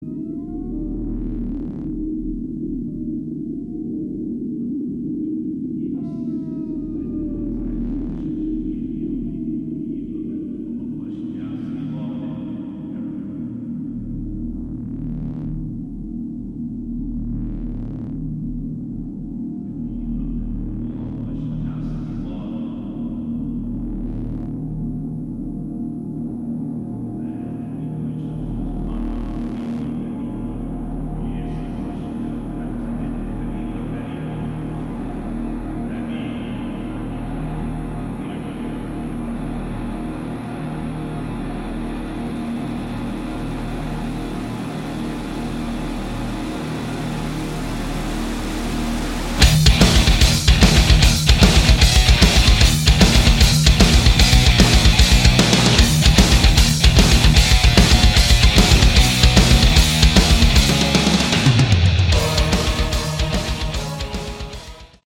Category: Hard Rock
lead vocals, guitar
drums, background vocals